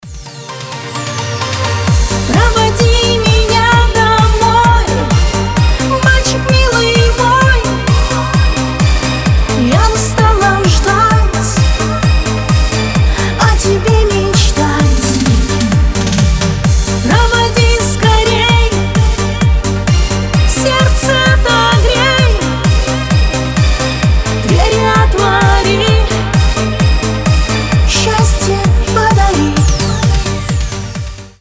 • Качество: 320, Stereo
громкие
женский голос